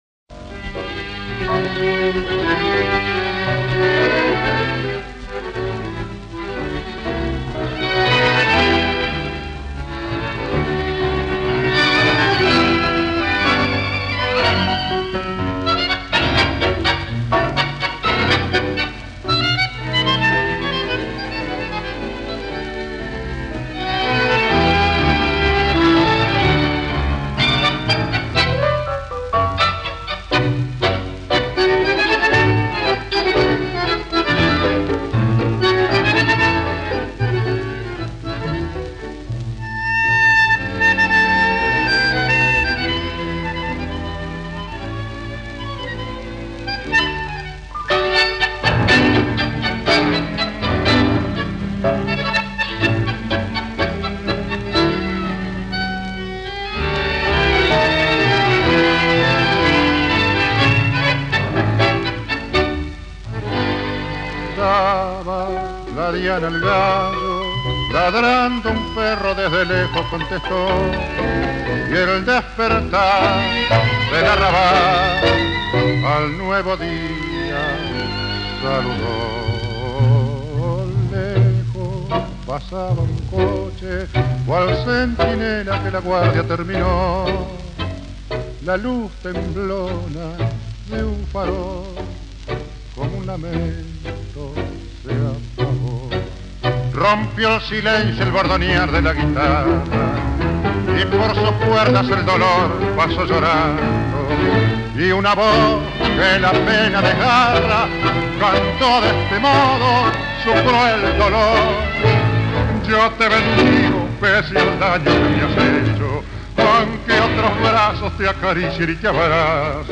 singer